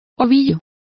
Complete with pronunciation of the translation of balls.